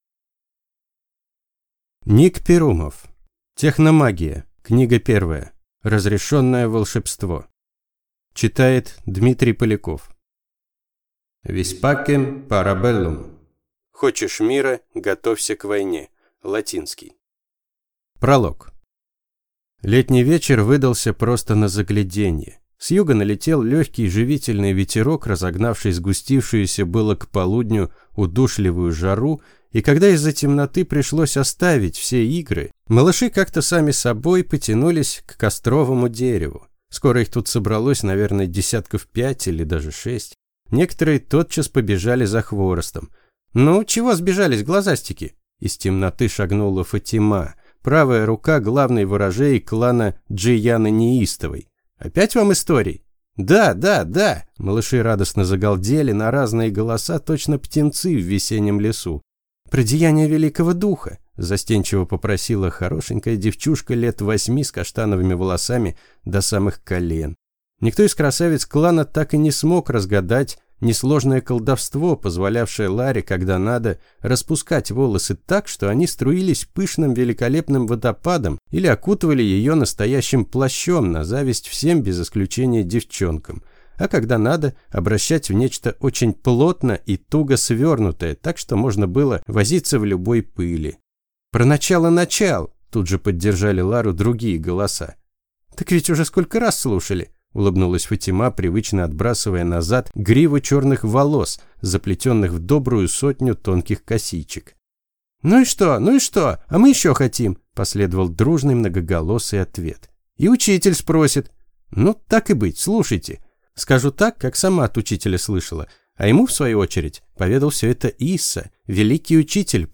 Аудиокнига Разрешенное волшебство | Библиотека аудиокниг